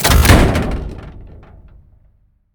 reload1.ogg